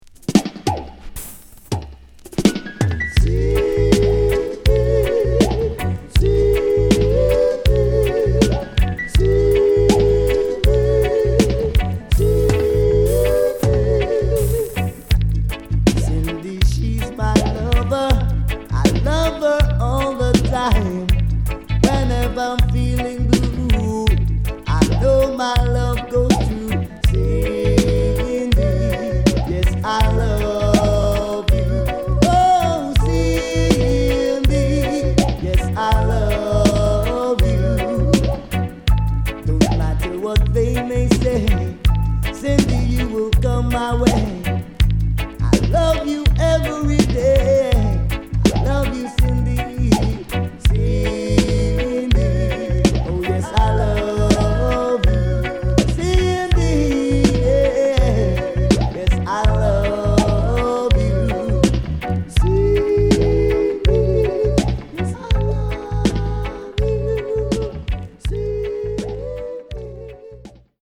HOME > Back Order [DANCEHALL DISCO45]  >  定番DANCEHALL